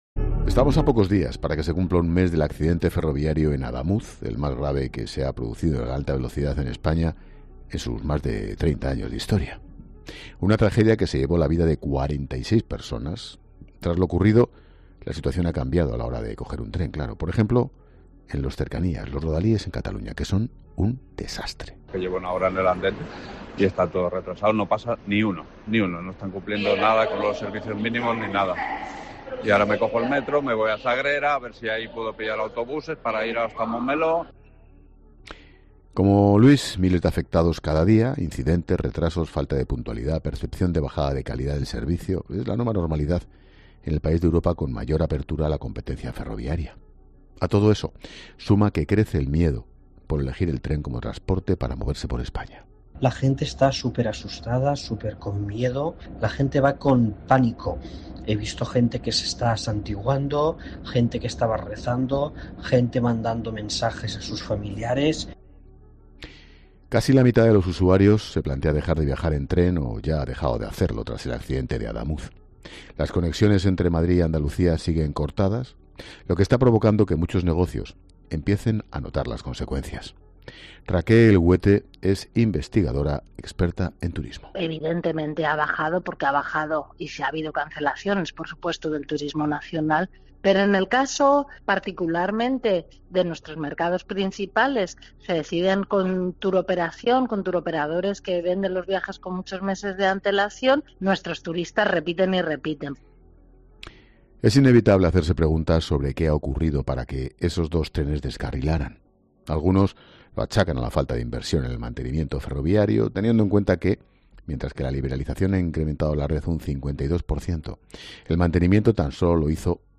Durante una intervención en el programa ‘La Linterna’ de COPE